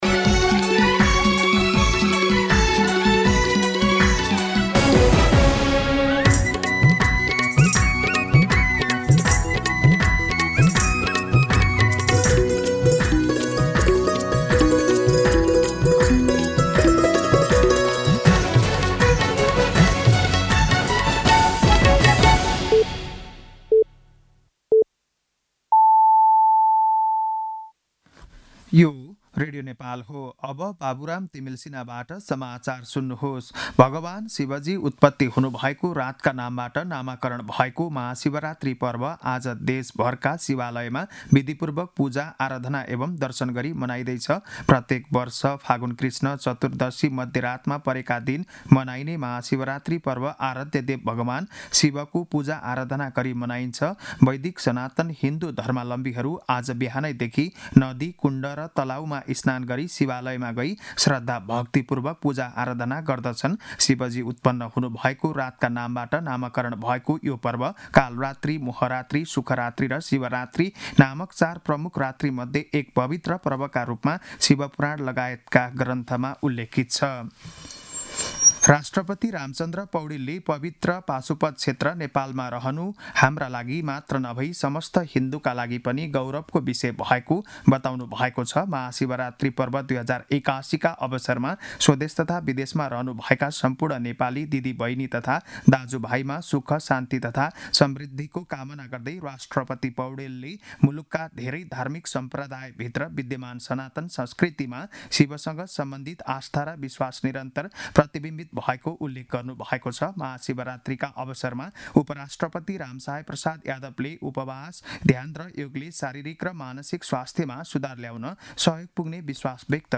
बिहान ११ बजेको नेपाली समाचार : १५ फागुन , २०८१
11am-News-14.mp3